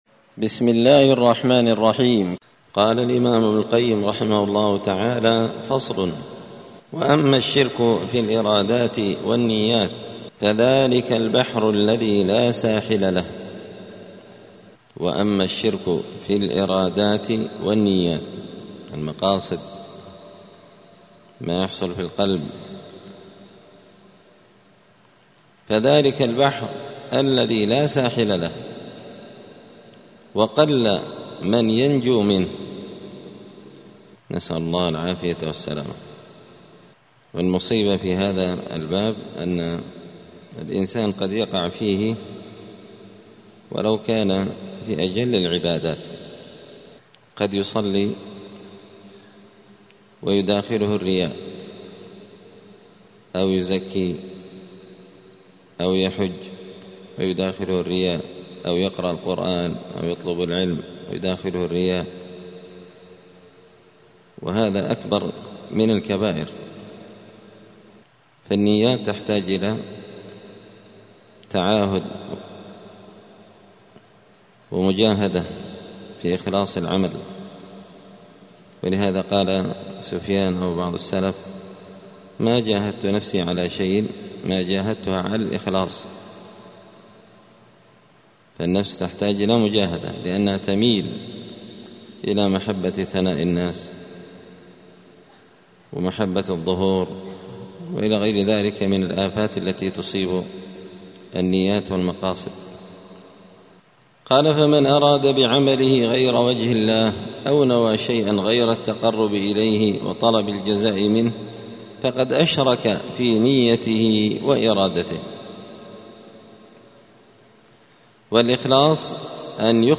*الدرس الحادي والستون (61) فصل الشرك في الإرادات والنيات*